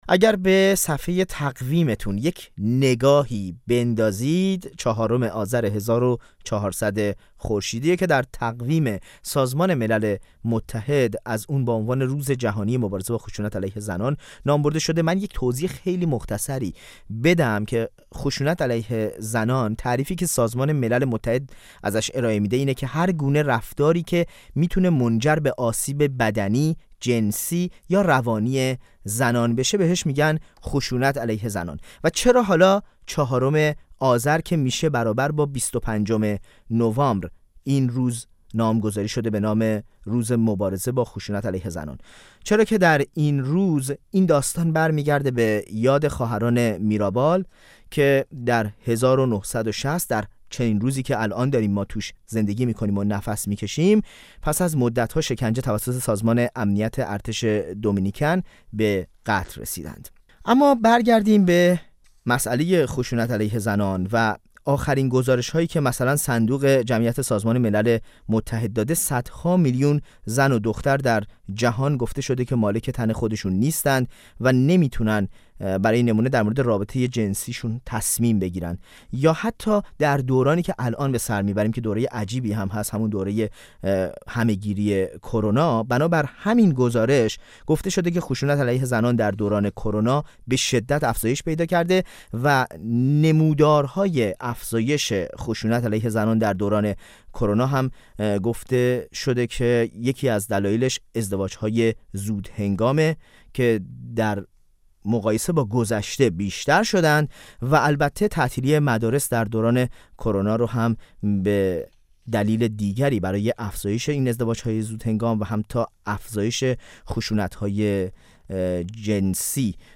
میزگرد: بازتولید خشونت با الگوی حاکمیتی زن در جمهوری اسلامی